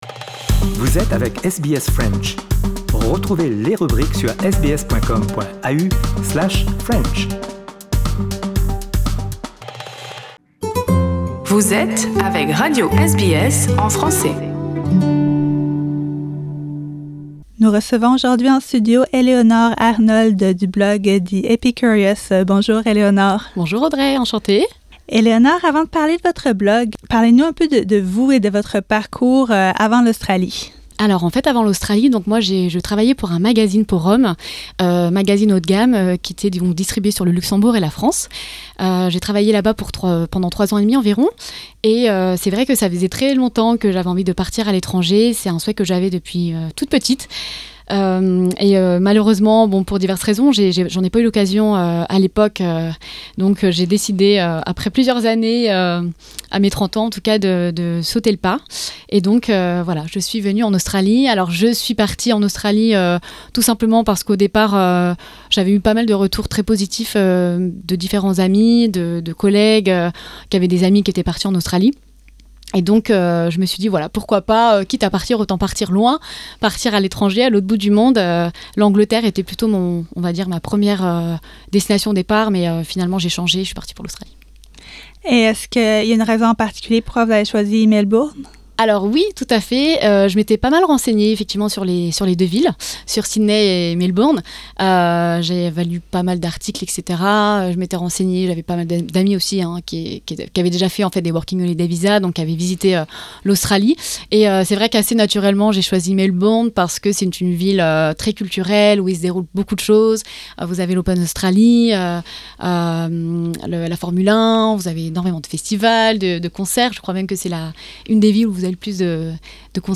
Elle est venue nous visiter en studio.